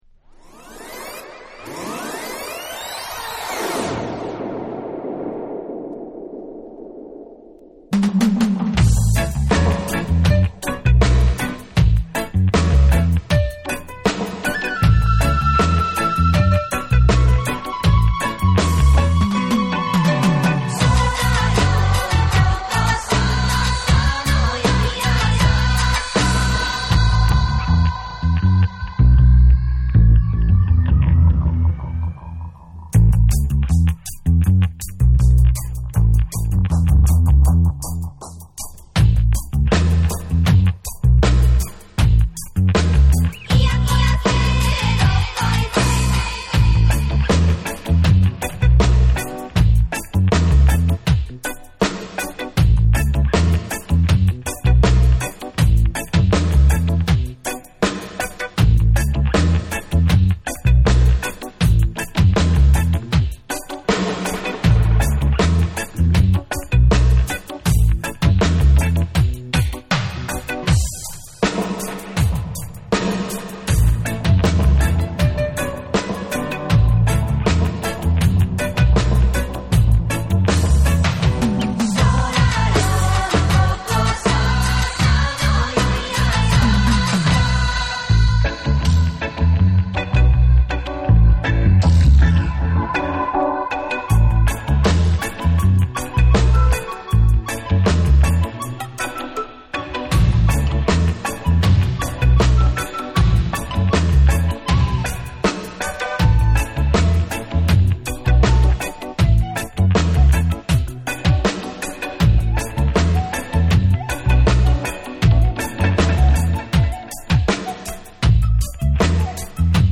レゲエ×河内音頭！
JAPANESE / REGGAE & DUB